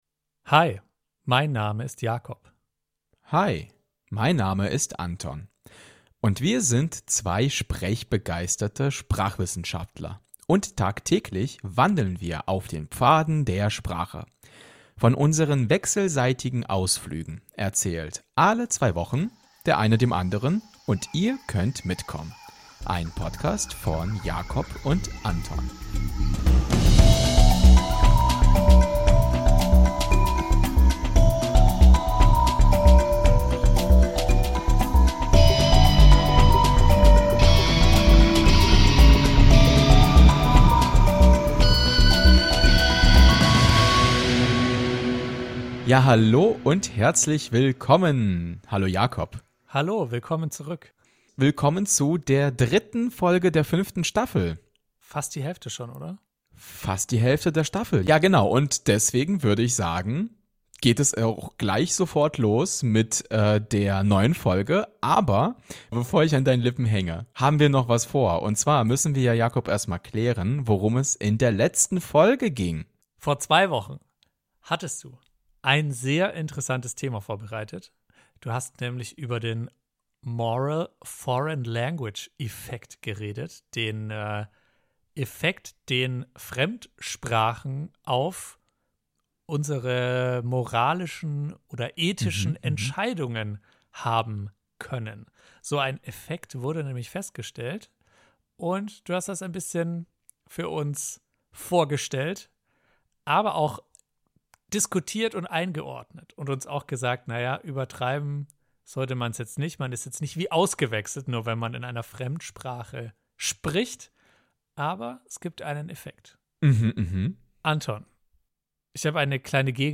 Beschreibung vor 4 Monaten In dieser Folge wagen wir uns auf okkulte Pfade und besprechen die aus dem Frühmittelalter überlieferten Zaubersprüche des Althochdeutschen. Neben den originalen Zaubersprüchen bekommt ihr obendrein auch noch die wissenschaftlich fundierte Erklärung zu hören, wie Zaubersprüche überhaupt funktionieren (sollen).